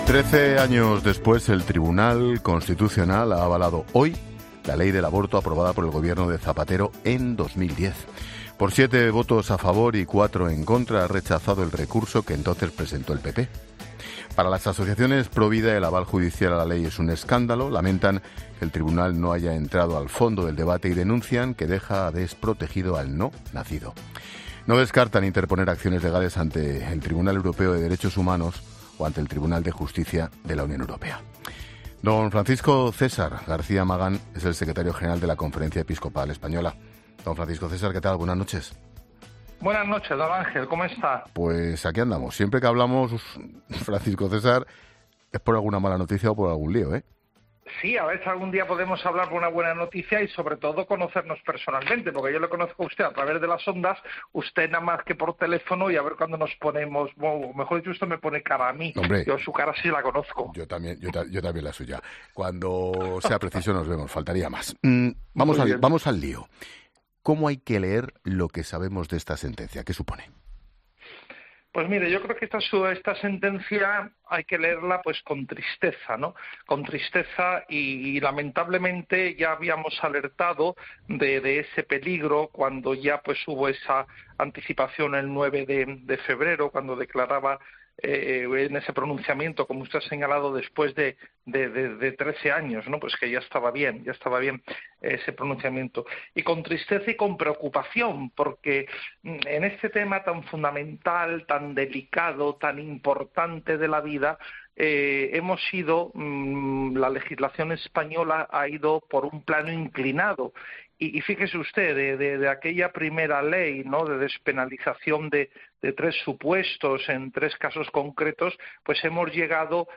El secretario general de la CEE profundiza con Ángel Expósito en La Linterna sobre la decisión del Tribunal Constitucional ante el aborto